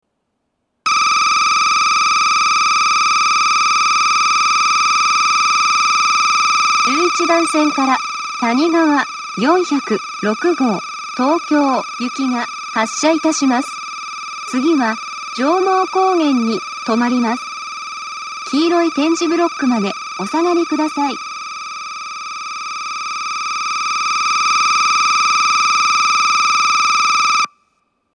２０２１年１０月１日にはCOSMOS連動の放送が更新され、HOYA製の合成音声による放送になっています。
１１番線発車ベル たにがわ４０６号東京行の放送です。